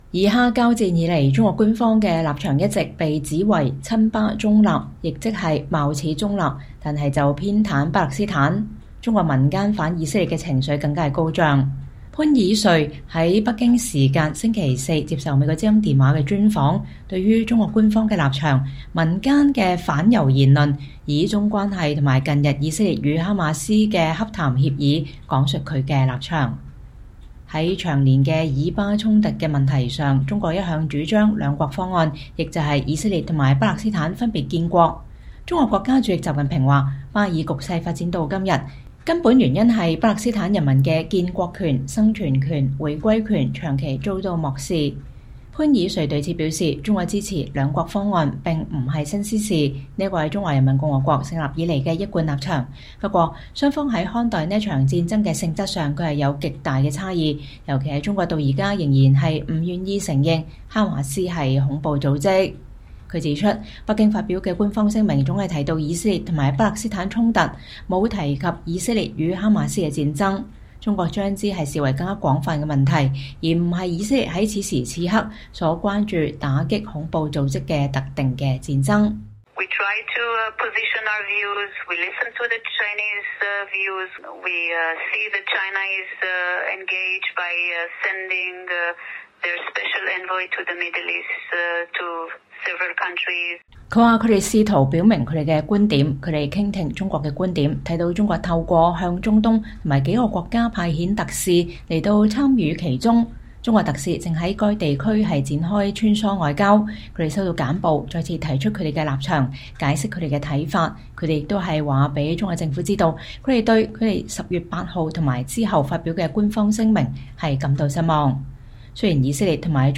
專訪以色列駐華大使：中國對以哈衝突扭曲報道 願與中國民眾直接對話